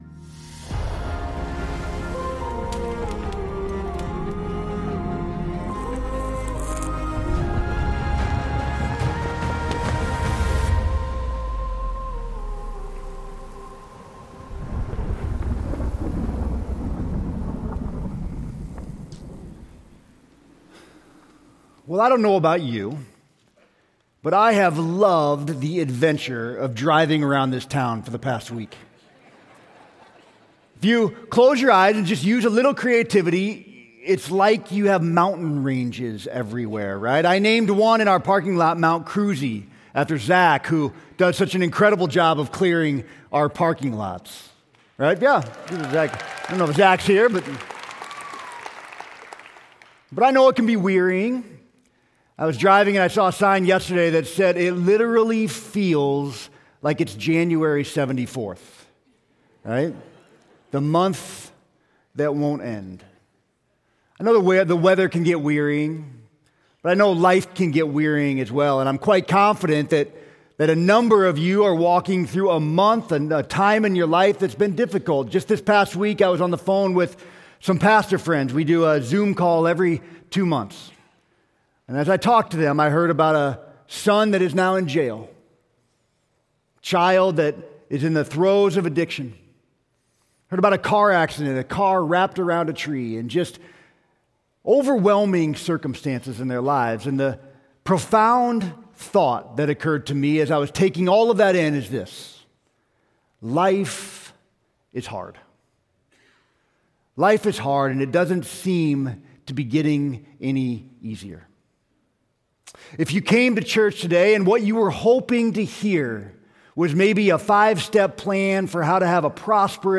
Sermon: Testing In the Wilderness